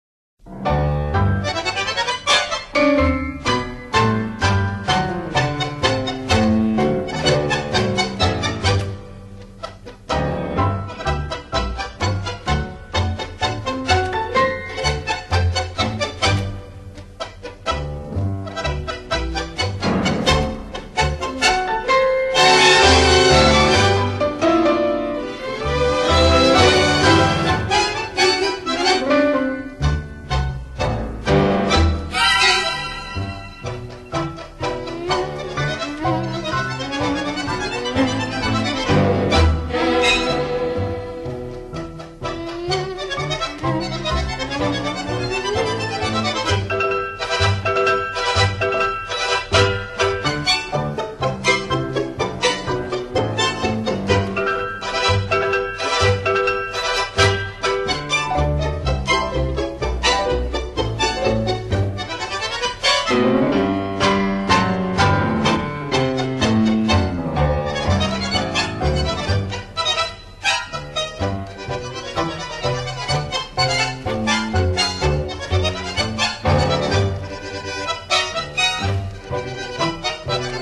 L'un des premiers Tango :